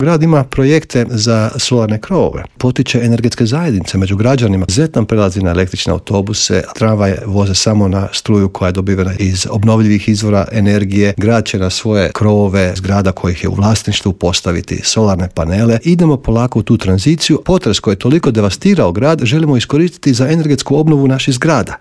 U Intervjuu Media servisa gostovao je predsjednik zagrebačke Gradske skupštine, Joško Klisović, koji je istaknuo važnost konferencije, njezine ciljeve i objasnio koliko je bitna uloga građana.